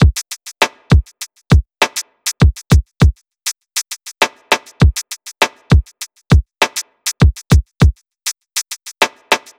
TI100BEAT1-R.wav